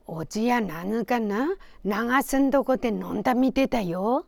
Aizu Dialect Database
Final intonation: Rising
Location: Aizumisatomachi/会津美里町
Sex: Female